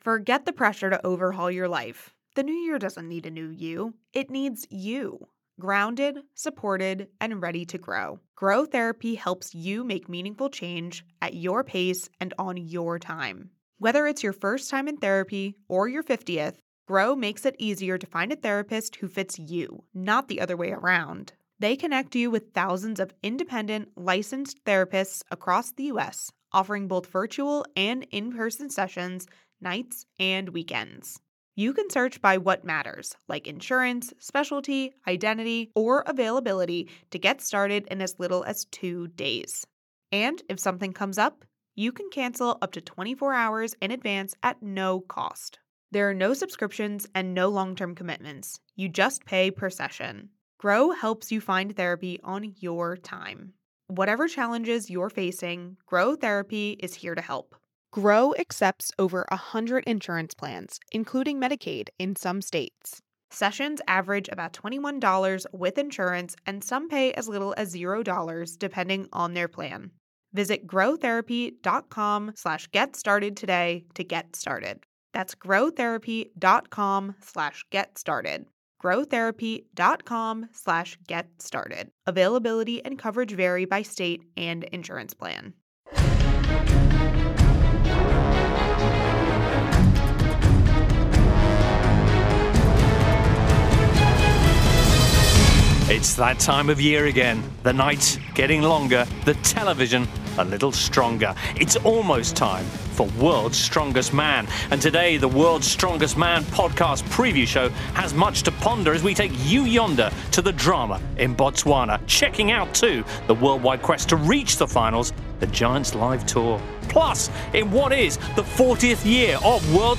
Plus there’s also a special look back at 40 years of World’s Strongest Man including an exclusive interview with four-time champion Magnus Ver Magnusson.